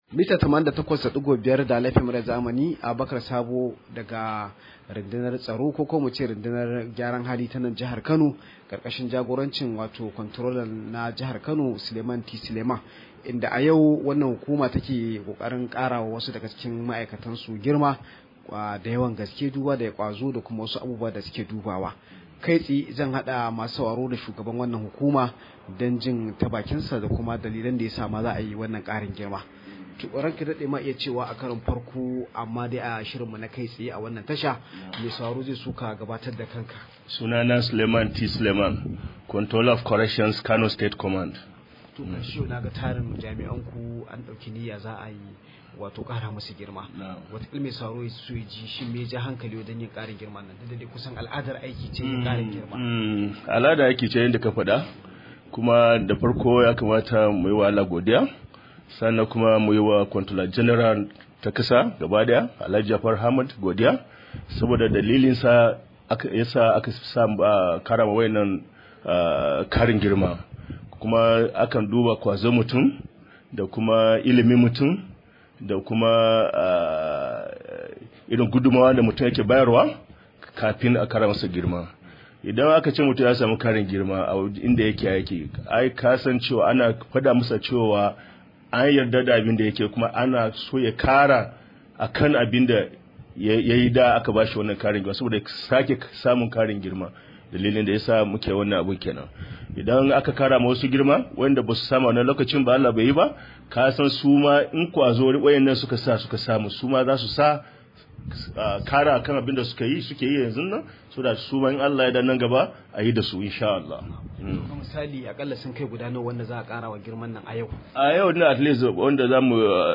Rahoto: Hukumar gidan ajiya da gyaran hali ta karawa jami’an ta girma